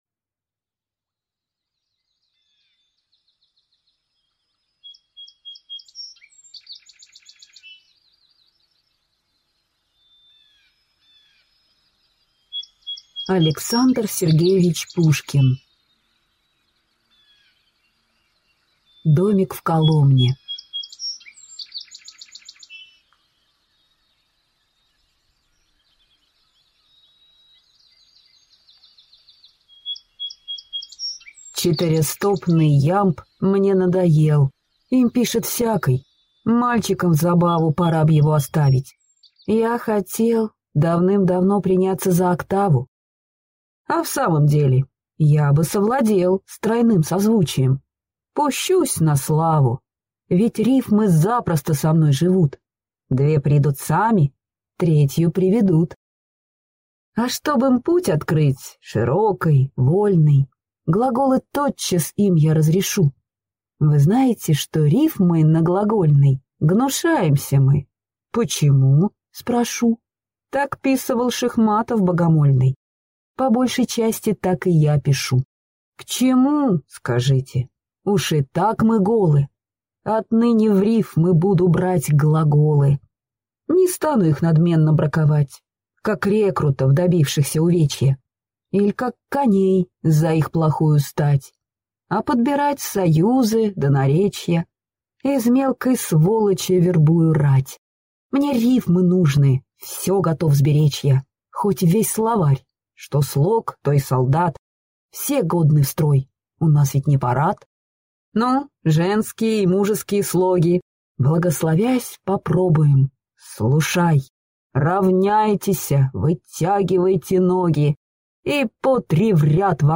Аудиокнига Домик в Коломне | Библиотека аудиокниг